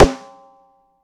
gretsch rim ff.wav